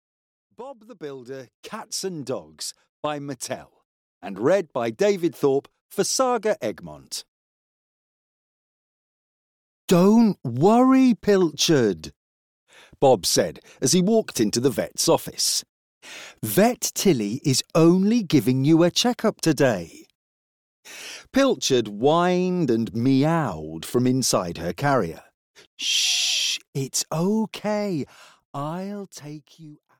Audio knihaBob the Builder: Cats and Dogs (EN)
Ukázka z knihy